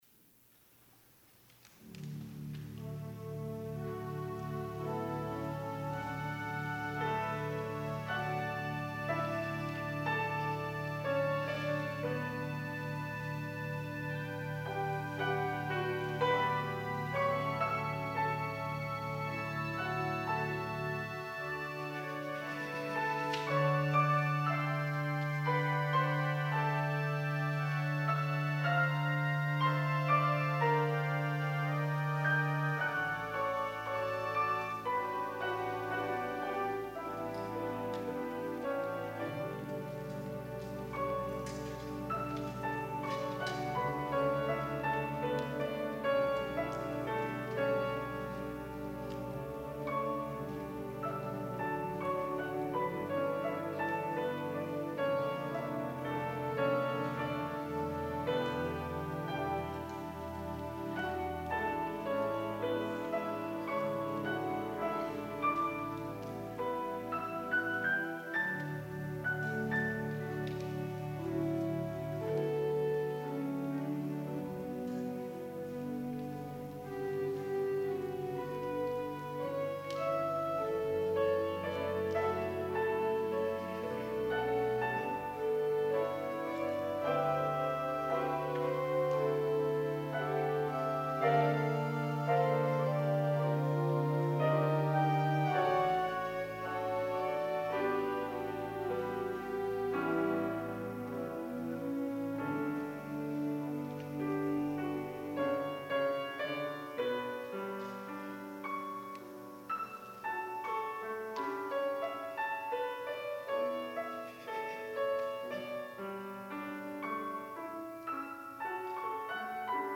piano
organ